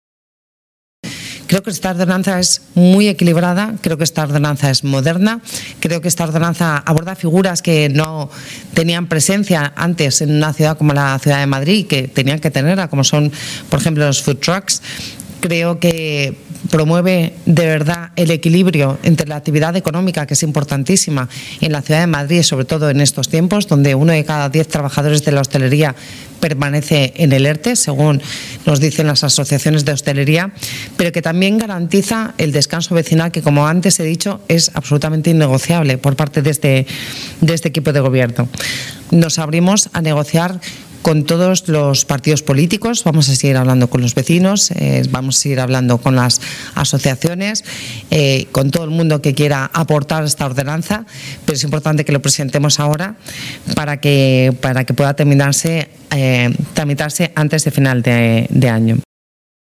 Nueva ventana:La vicealcaldesa, Begoña Villacís, explica en rueda de prensa, la nueva ordenanza de terrazas